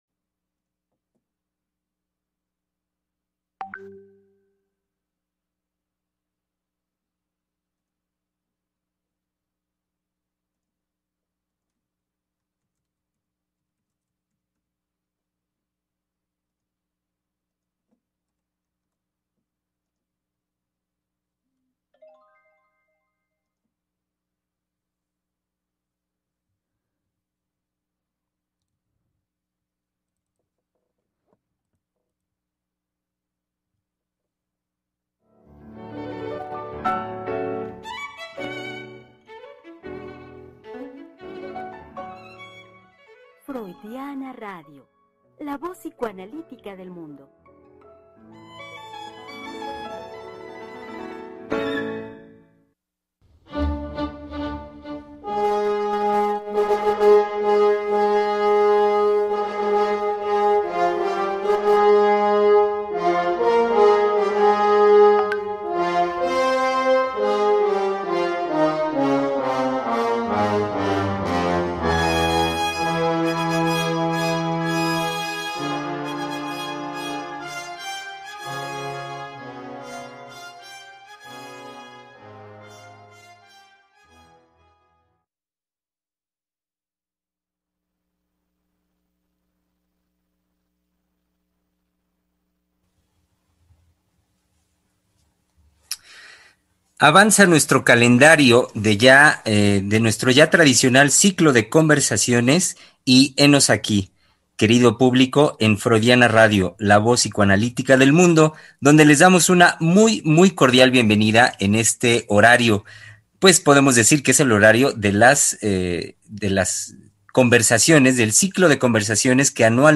Conversación con motivo del día del niño con los psicoanalistas del Centro de Investigación y Estudios Lacanianos, A.C.